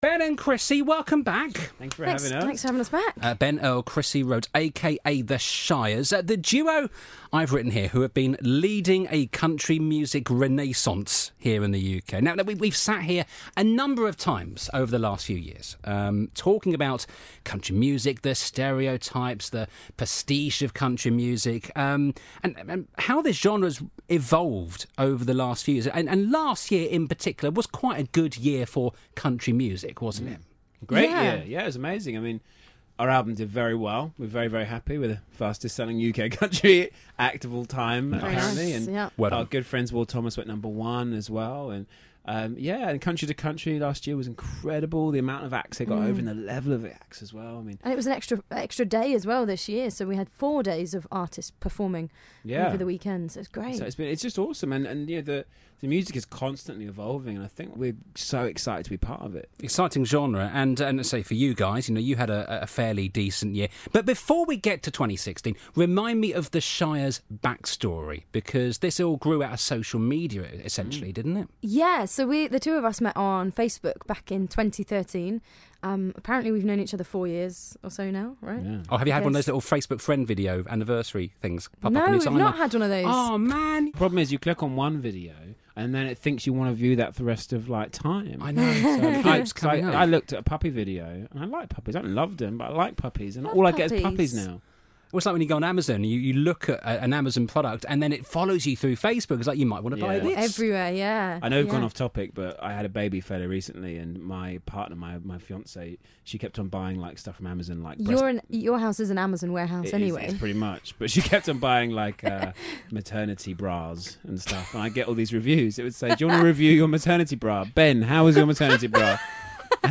country music sensations THE SHIRES in for a chat and session